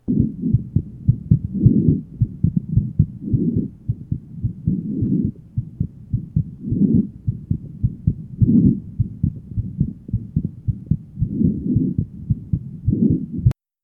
Date 1972 Type Systolic and Diastolic Abnormality Atrial Septal Defect ASD with atypical murmur; soft pulmonary ejection murmur, split S2 varies slightly, diastolic murmur loudest at apex To listen, click on the link below.